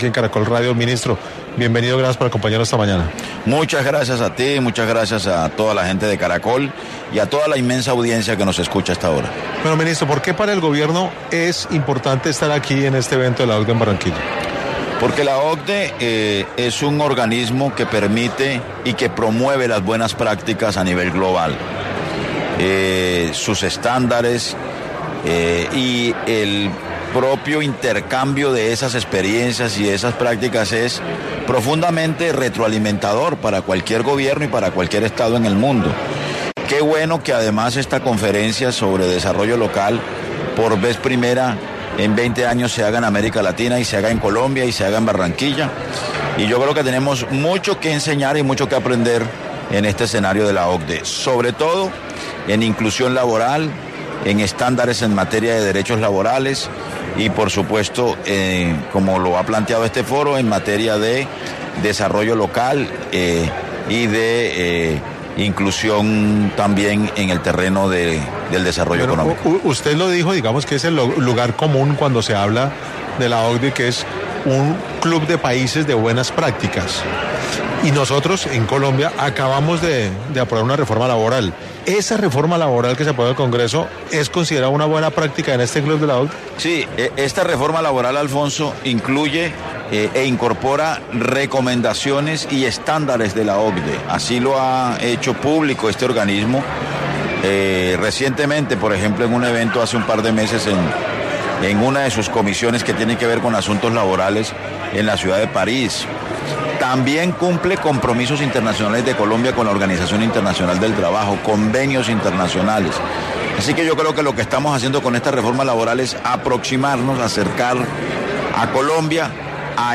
En entrevista con 6AM de Caracol Radio, el ministro destacó la importancia de este organismo para promover las buenas prácticas a nivel global y el intercambio de experiencias.